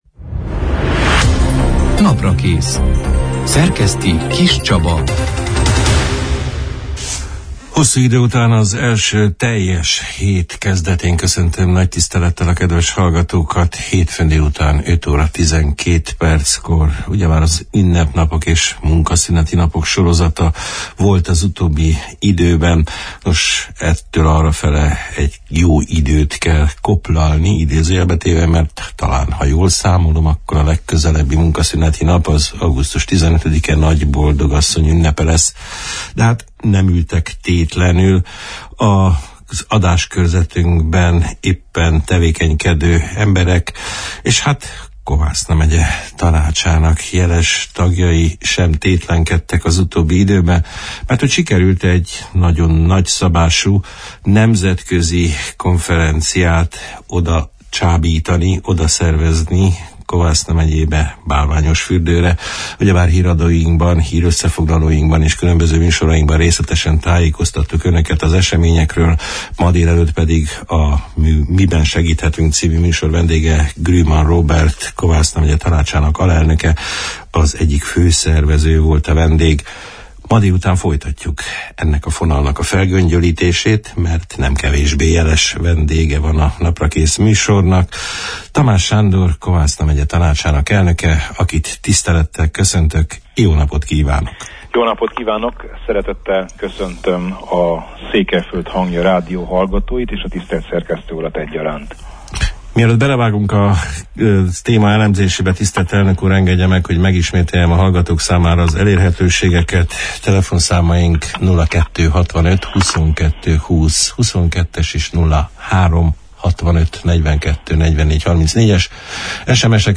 A Bálványosfürdőn lezajlott Európa Tanács – i önkormányzati kongresszusa szakbizottságának május végi, kihelyezett űlése tapasztalatairól, a résztvevőkkel történt egyeztetések következtetéseiről, az apró incidensek befolyásáról, a megye fejlesztési terveiről, az elesettek megsegítésének konkrétumairól, megemlékezések napirendjéről beszélgettünk a június 4 – én, hétfőn elhangzott Naprakész műsorban Tamás Sándorral, Kovászna Megye Tanácsának elnökével.